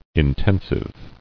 [in·ten·sive]